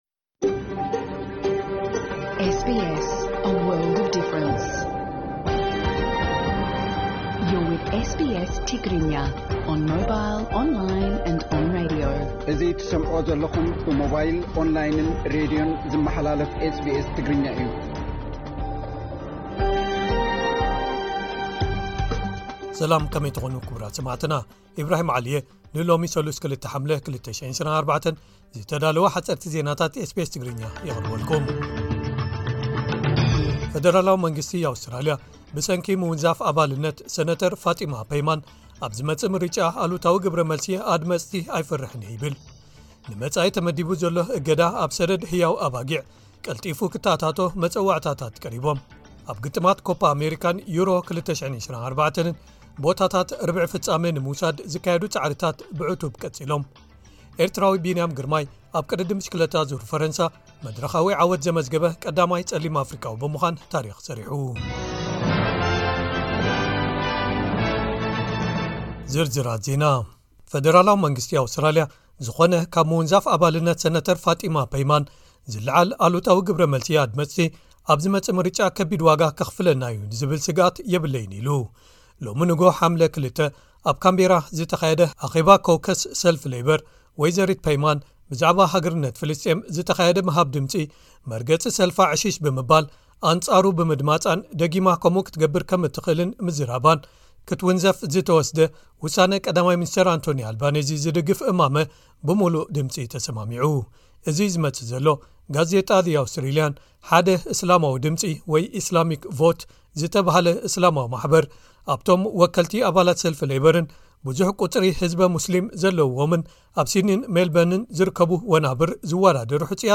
ሓጸርቲ ዜናታት ኤስ ቢ ኤስ ትግርኛ (02 ሓምለ 2024)